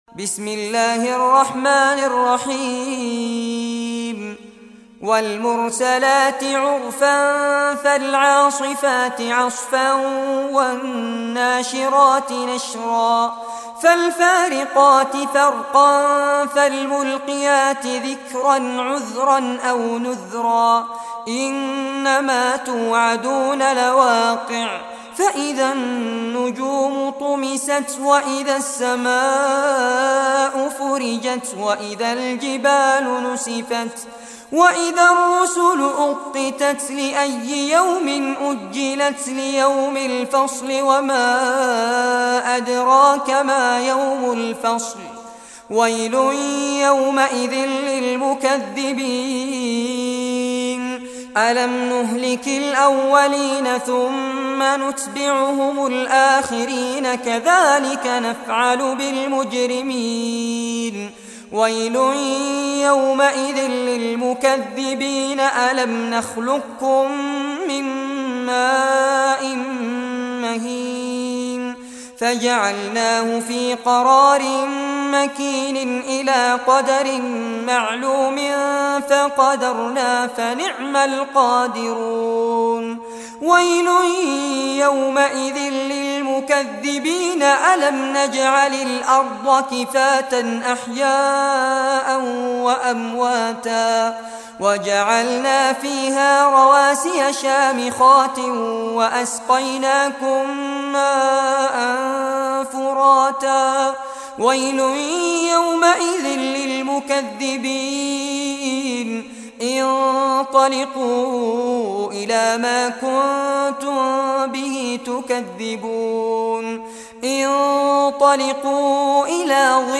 Surah Al-Mursalat Recitation by Fares Abbad
Surah Al-Mursalat, listen or play online mp3 tilawat / recitation in Arabic in the beautiful voice of Sheikh Fares Abbad, Download audio tilawat of Surah Al-Mursalat free mp3 in best audio quality.